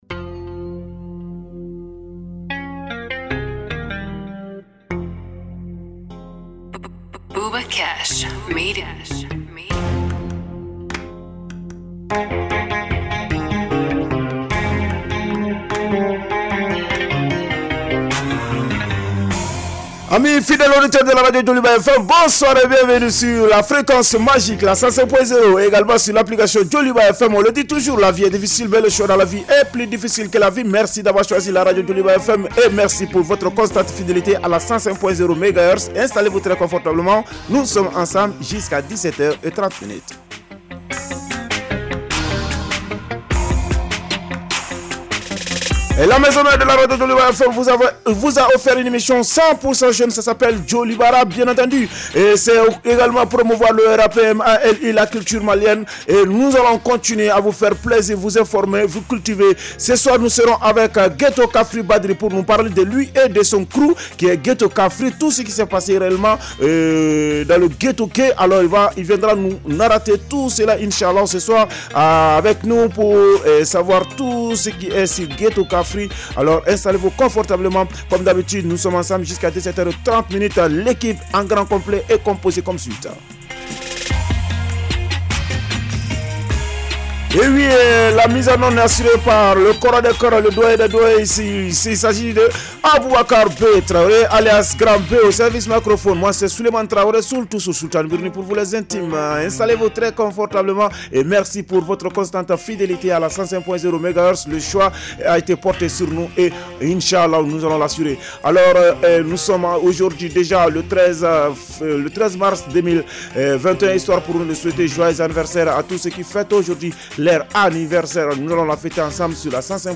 Un programme 100 % dédié à la scène rap et hip-hop du Mali avec des interviews exclusives, des freestyles et toute l’actualité croustillante de vos rappeurs préférés.